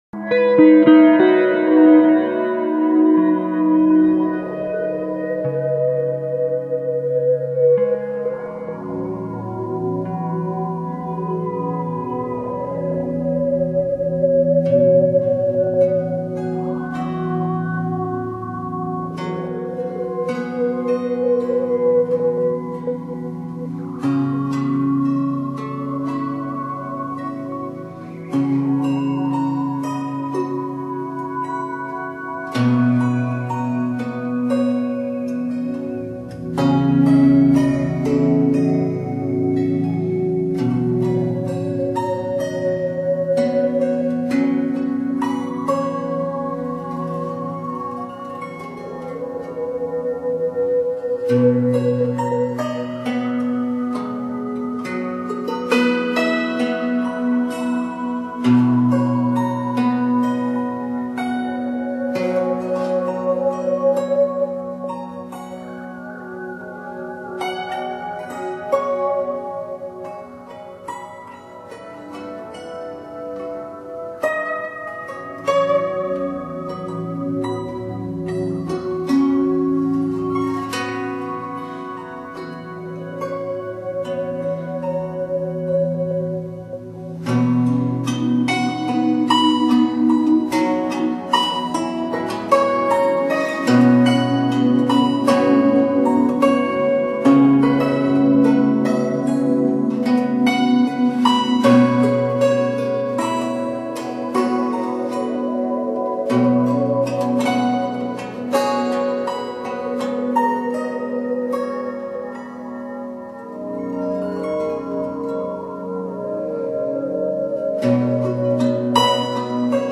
寄付着音乐缓缓的律动，